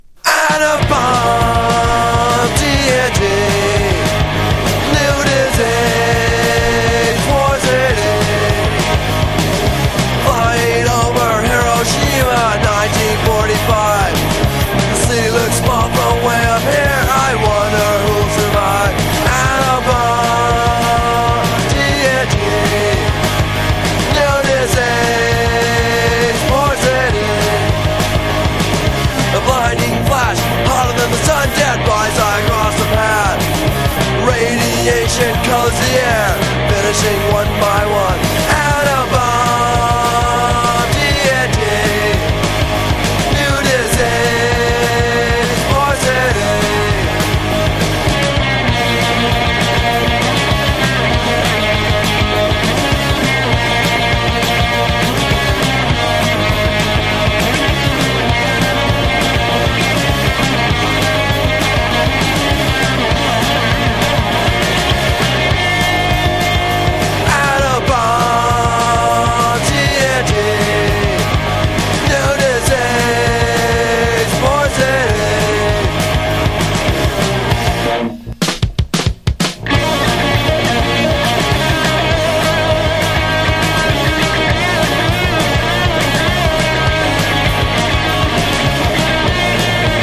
粗削りでロウなパンク。